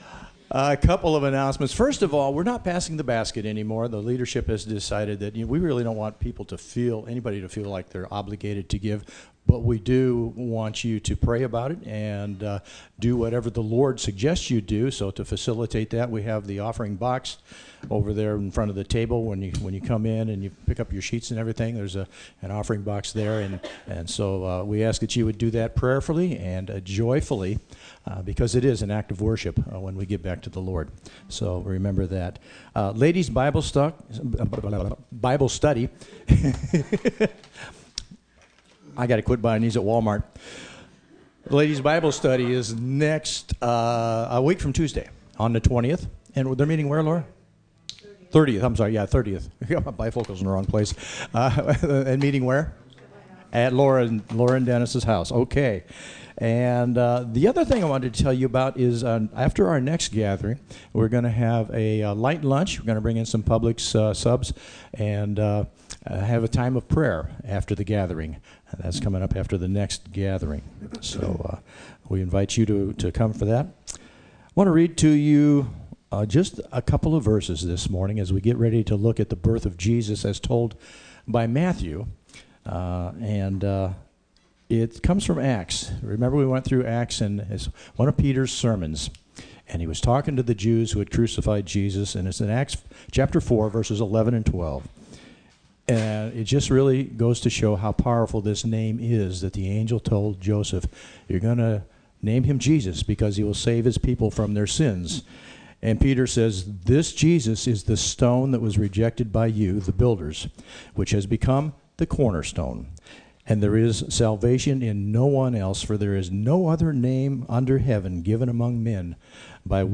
Matthew 1:18-25 Service Type: Gathering Luke tells of of the angel’s message to Mary and the strong faith she demonstrated.